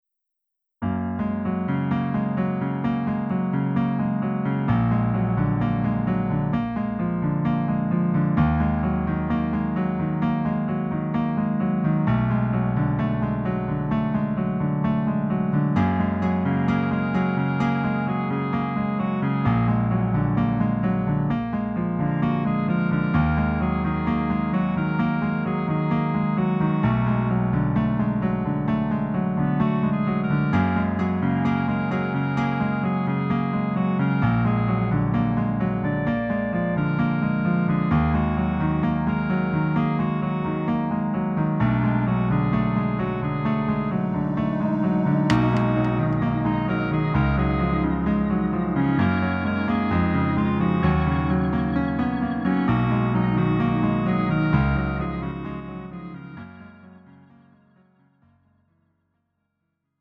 음정 -1키 4:07
장르 가요 구분 Lite MR
Lite MR은 저렴한 가격에 간단한 연습이나 취미용으로 활용할 수 있는 가벼운 반주입니다.